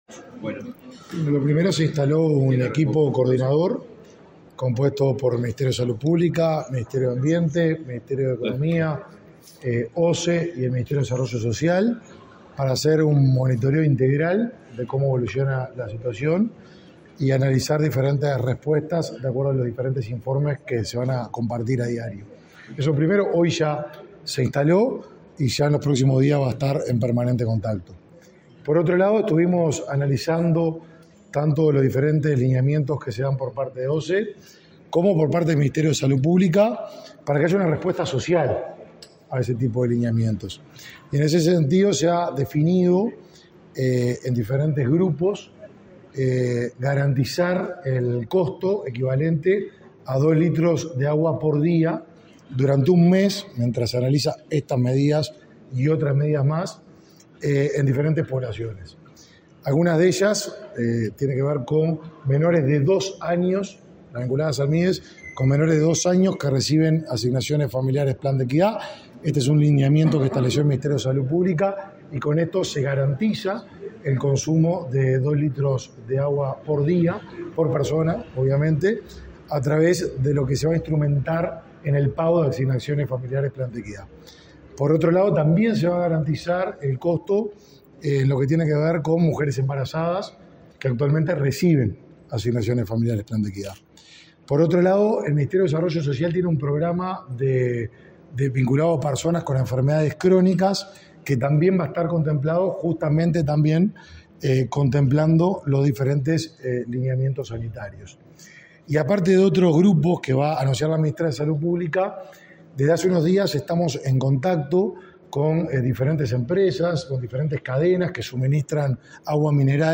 Conferencia de prensa para anunciar medidas ante problemática de abastecimiento de agua potable
conferencia.mp3